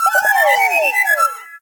appear.ogg